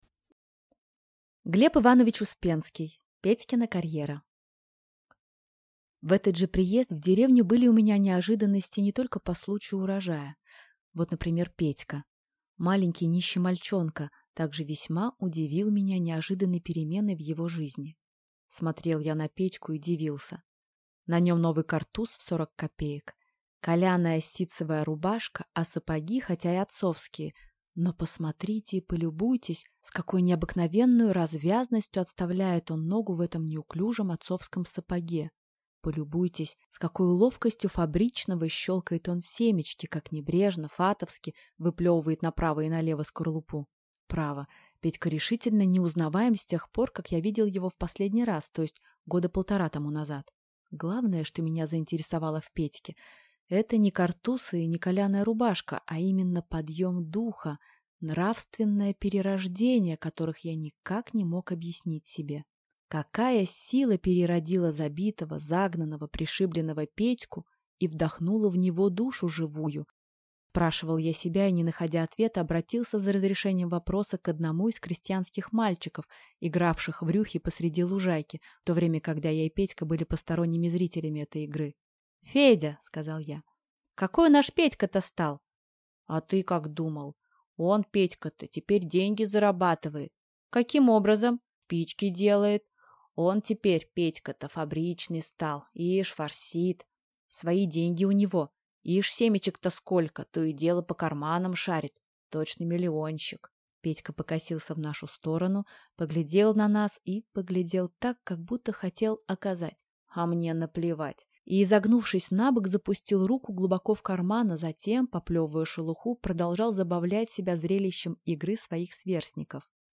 Аудиокнига Петькина карьера | Библиотека аудиокниг